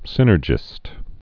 (sĭnər-jĭst)